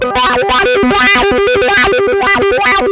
MANIC YODEL.wav